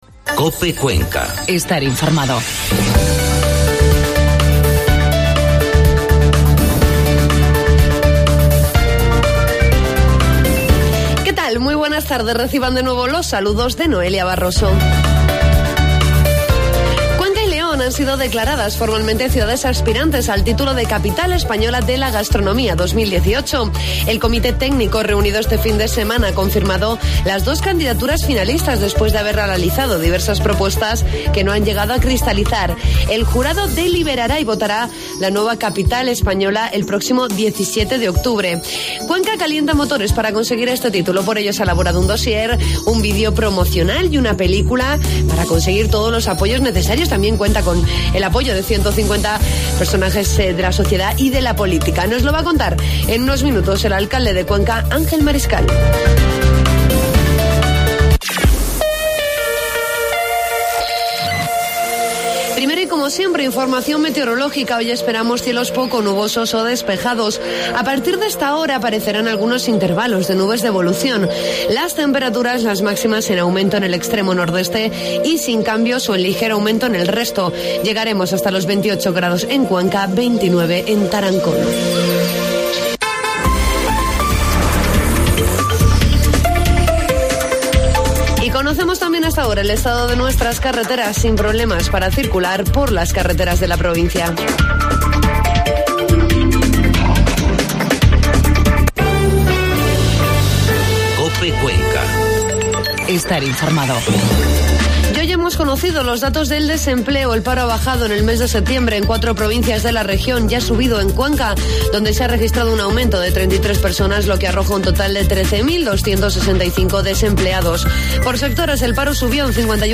Hablamos con el alcalde de Cuenca, Ángel Mariscal, sobre la candidatura de Cuenca a Capital Española de la Gastronomía 2018.